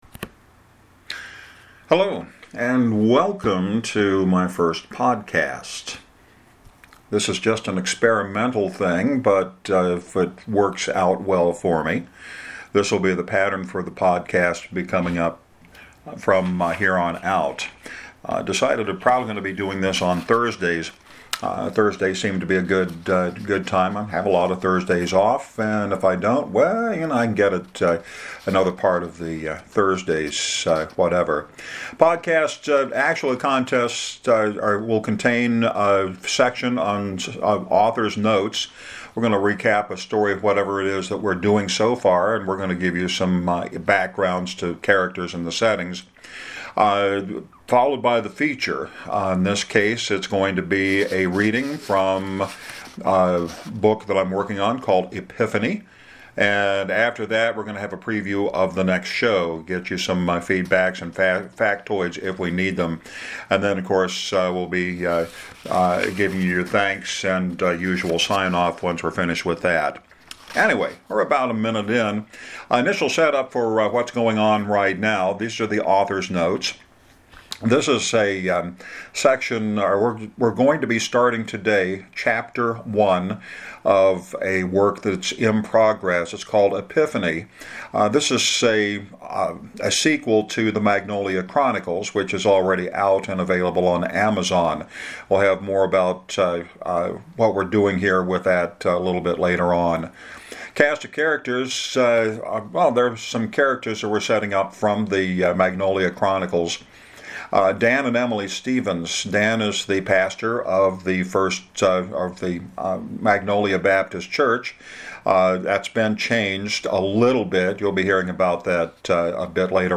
Keep in mind that the technology I’m using is new to me and that much of what you hear is off the cuff (well, except for the story).